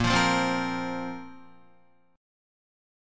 Bbsus2 chord